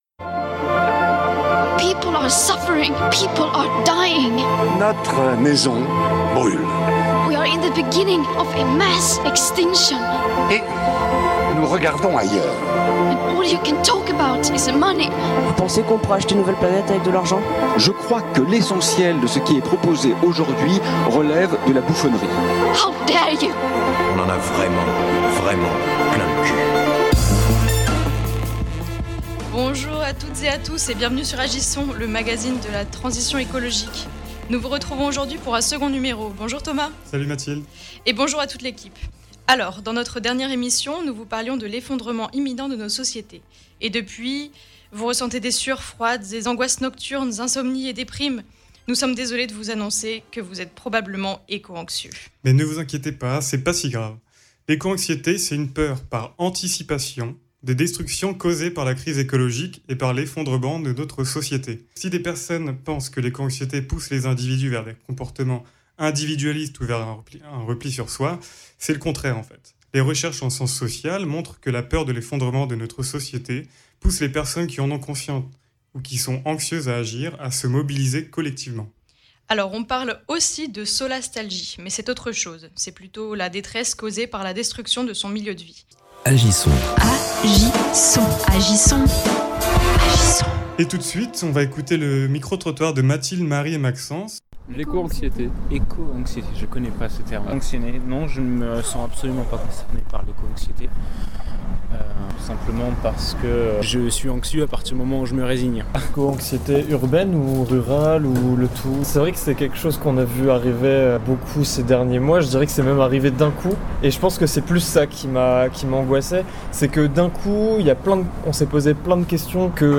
Conférence au CAPOP à Paris : La nature comme médiation thérapeutique